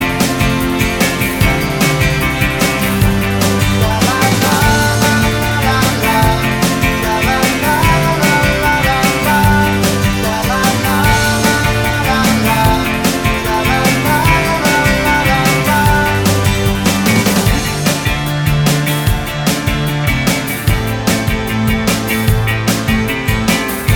no Backing Vocals Indie / Alternative 4:02 Buy £1.50